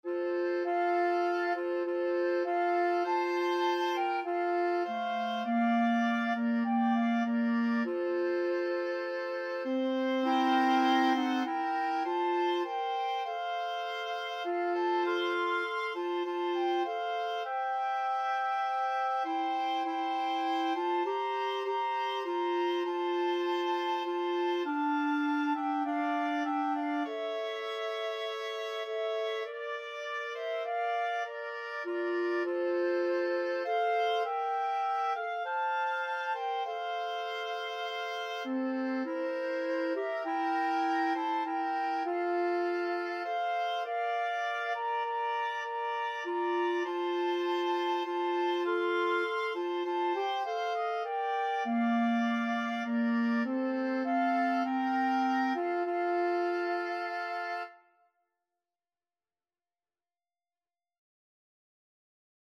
Free Sheet music for Woodwind Trio
FluteOboeClarinet
F major (Sounding Pitch) (View more F major Music for Woodwind Trio )
4/4 (View more 4/4 Music)
Woodwind Trio  (View more Easy Woodwind Trio Music)
Classical (View more Classical Woodwind Trio Music)